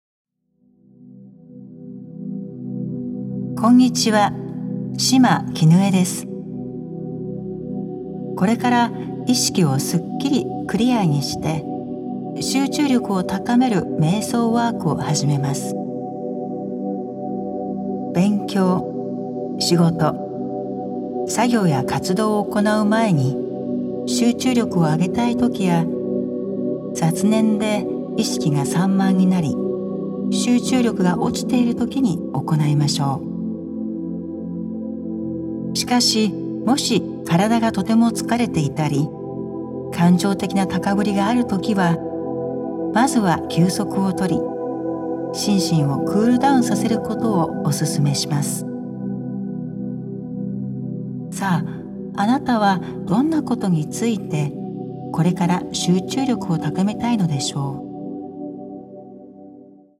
瞑想マントラ入りで、ヒーリングや癒し作用も。